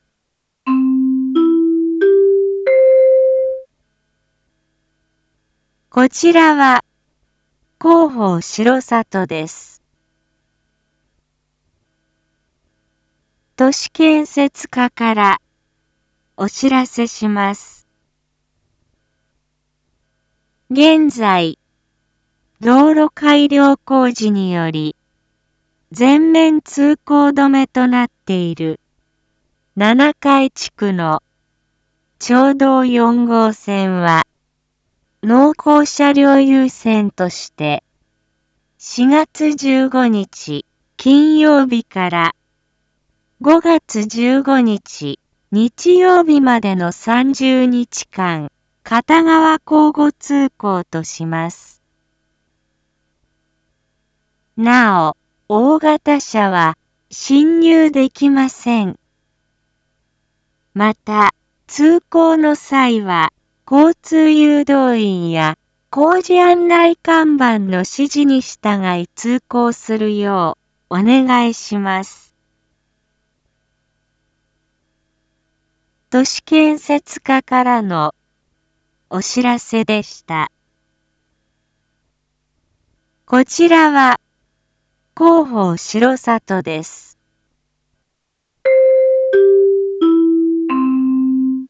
Back Home 一般放送情報 音声放送 再生 一般放送情報 登録日時：2022-04-12 07:01:33 タイトル：R4.4.12 7時放送分 インフォメーション：こちらは広報しろさとです。